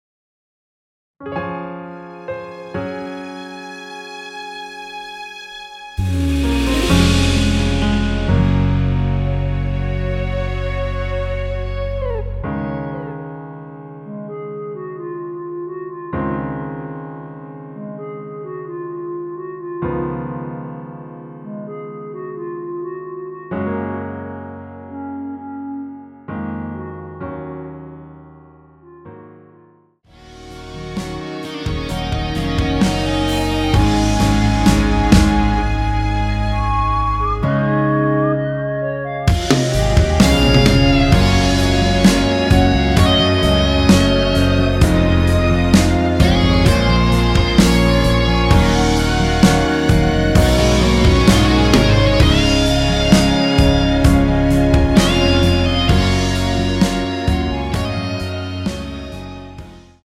원키에서(-2)내린 멜로디 포함된 MR입니다.
Db
앞부분30초, 뒷부분30초씩 편집해서 올려 드리고 있습니다.
중간에 음이 끈어지고 다시 나오는 이유는